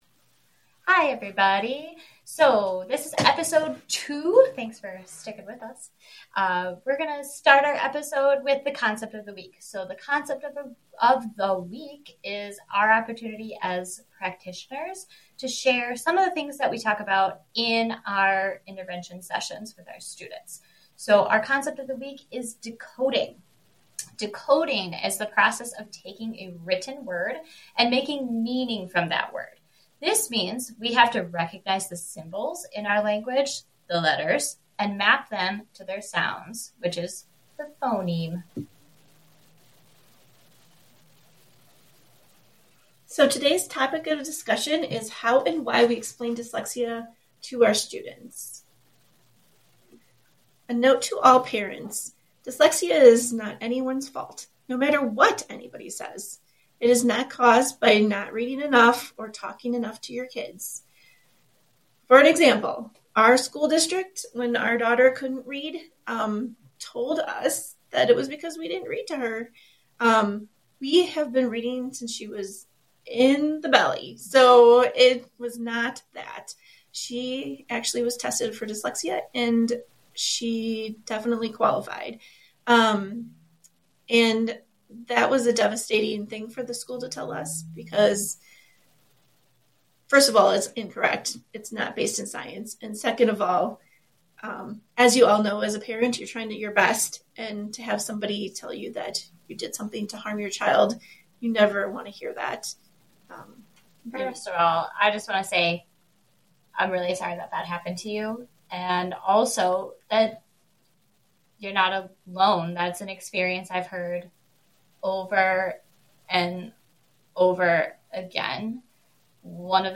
We are both moms and dyslexia interventionists who want to talk about our students and children.